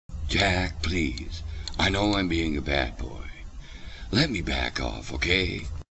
----------- I Always Had A Timed Voice For Jack Nickolson But Not With The Sound Made As He Makes It -----------